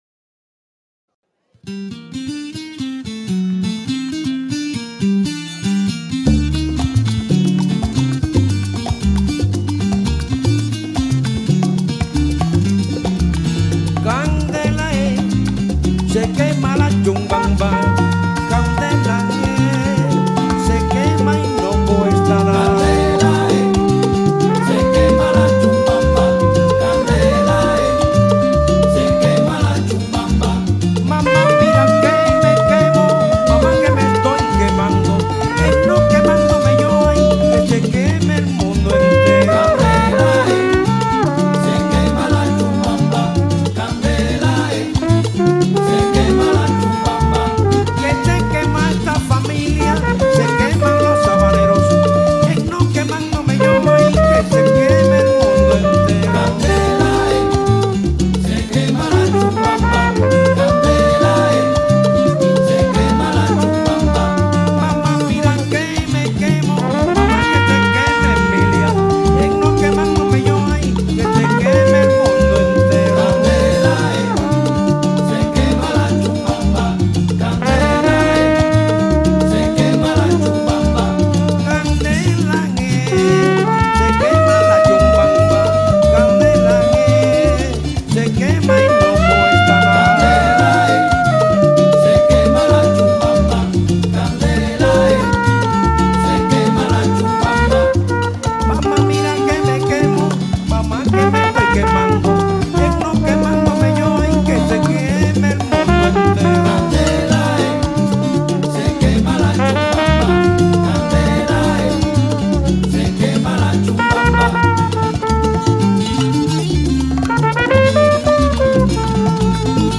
Improvisaties
Original Cuban Son Music